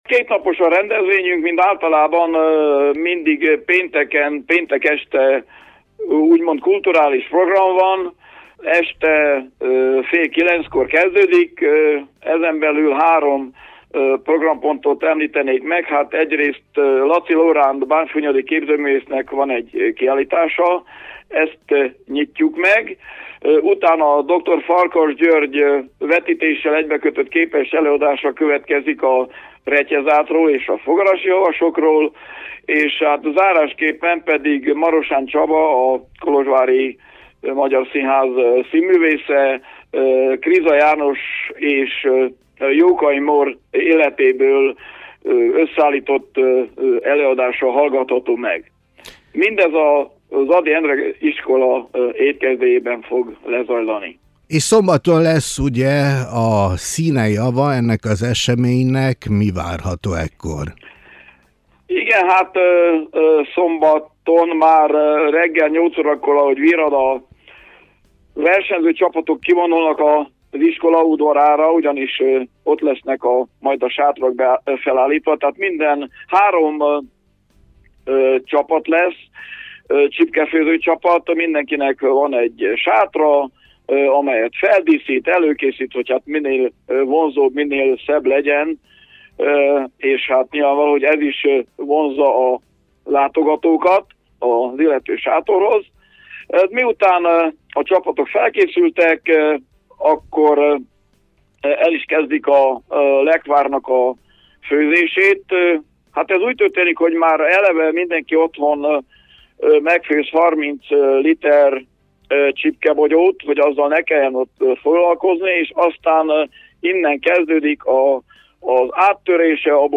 Riporter: